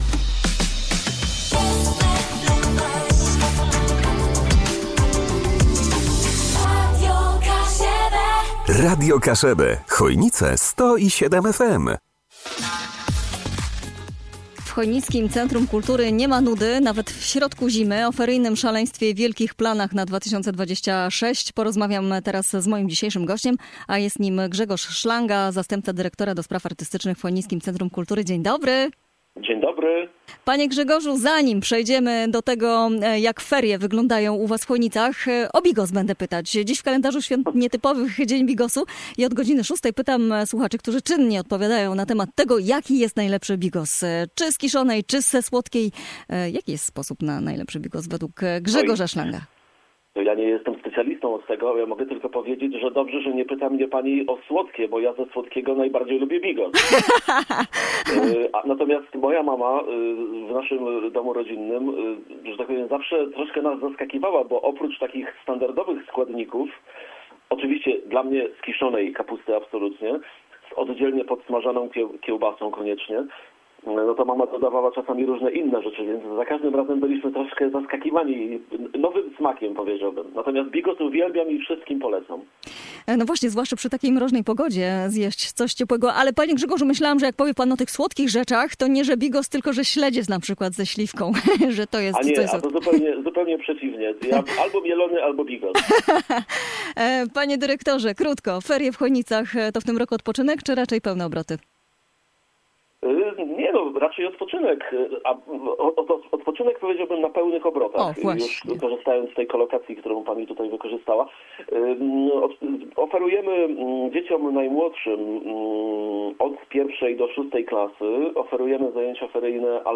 na antenie Radia Kaszëbë zdradził szczegóły dotyczące trwających ferii zimowych oraz nadchodzących atrakcji kulturalnych.